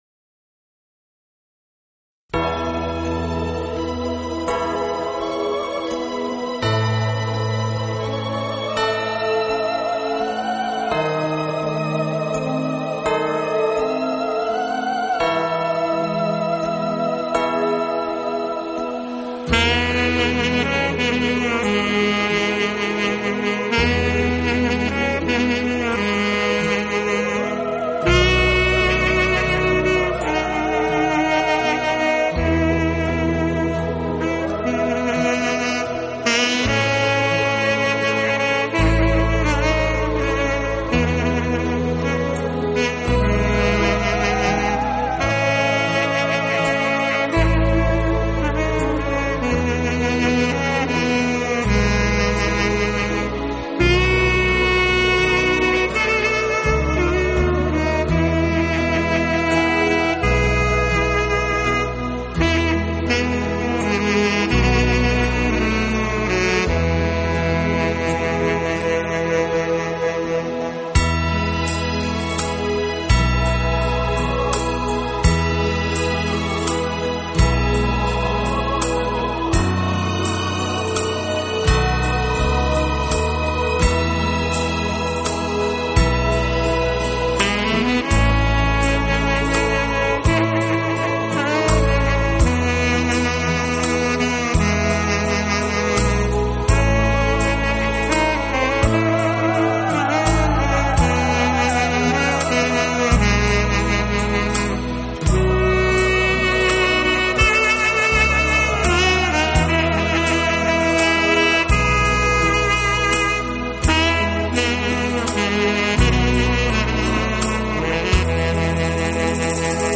这丝丝情意像恋人般彼此依偎，轻轻地，静静地直到永远！